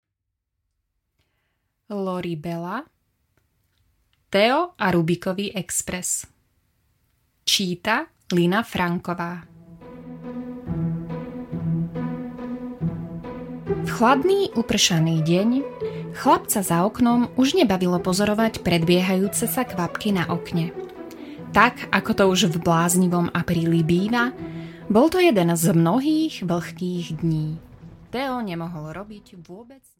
Teo a Rubikový Express audiokniha
Ukázka z knihy